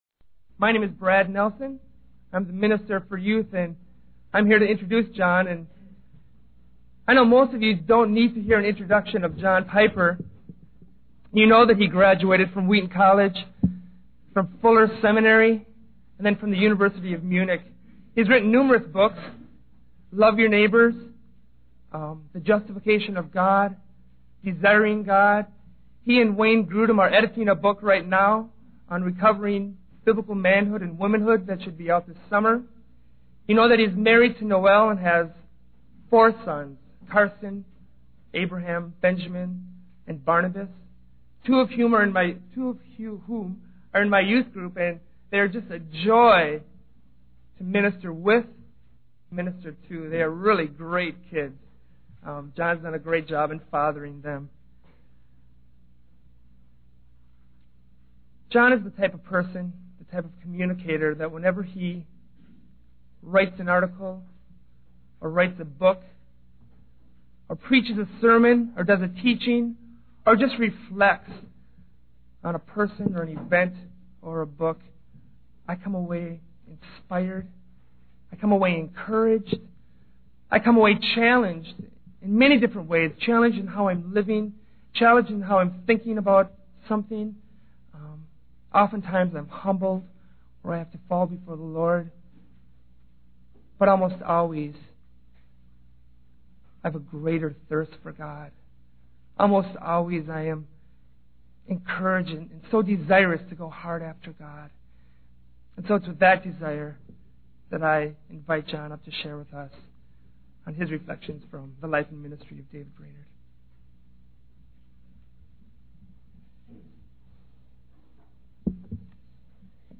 The video is a sermon about the life and ministry of David Brainerd. The speaker highlights the struggles that Brainerd faced, including loneliness, physical ailments, and spiritual battles.